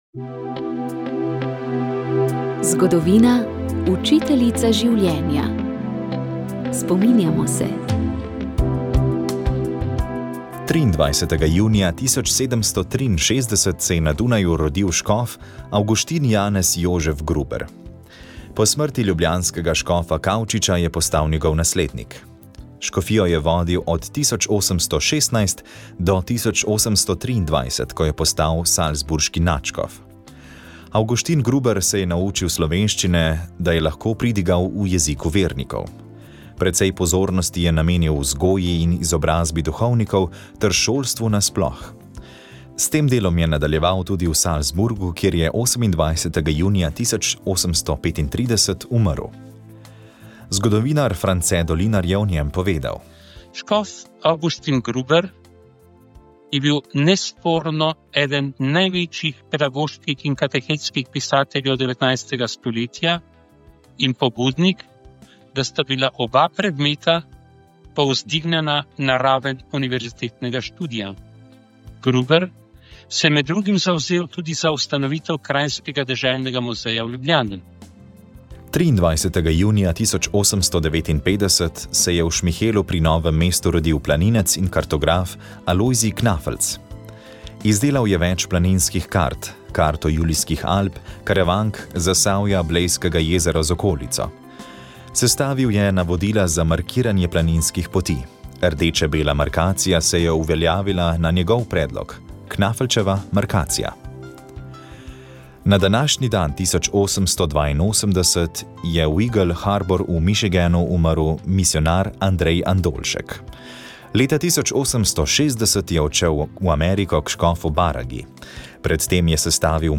V oddaji Moja zgodba lahko prisluhnete še zadnjima dvema predavanjema s simpozija z naslovom Tragedija 1941, ki je potekal ob 80. letnici začetka druge svetovne vojne, junija letos, v Parku vojaške zgodovine v Pivki.